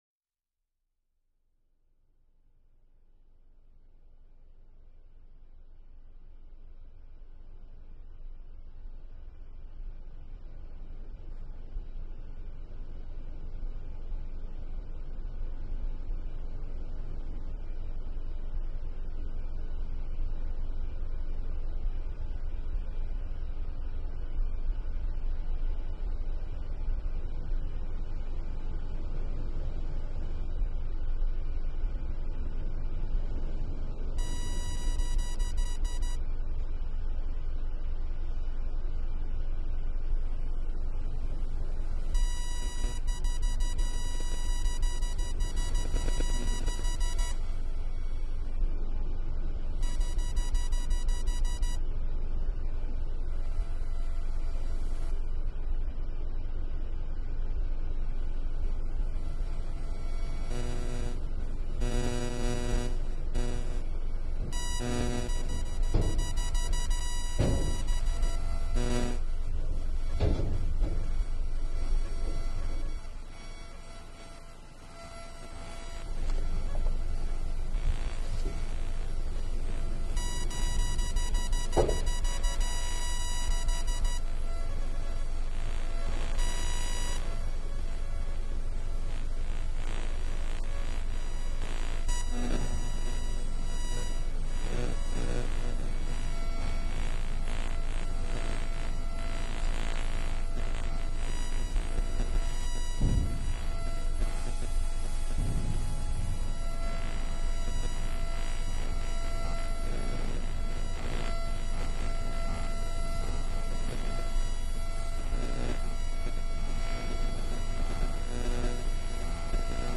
A composition created for the program series “Transmission Ecologies”, based on an acoustic research that addresses the human footprint left by extraction techniques in uninhabited places, focusing on the present and future of the largest lithium salt deposit on the planet, located in the Salar of Tunupa (also known as Salar de Uyuni).